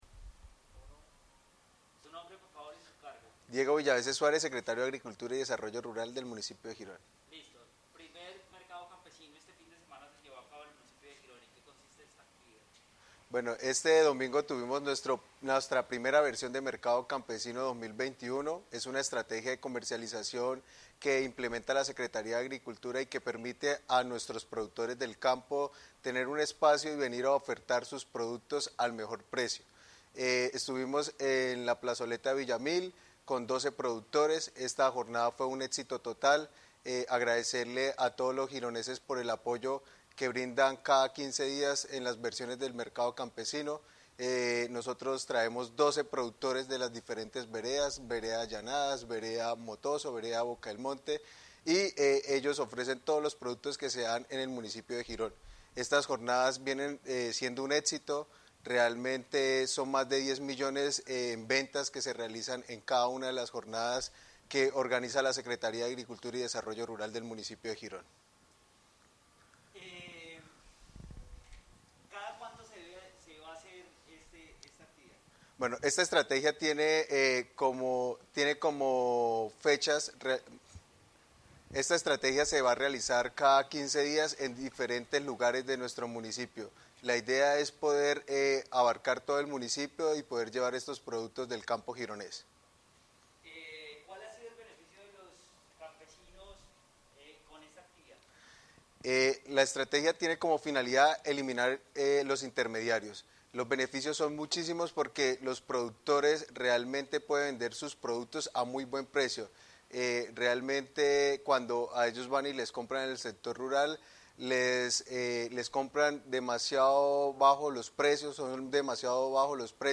Diego Villaveces, Secretario de Agricultura.mp3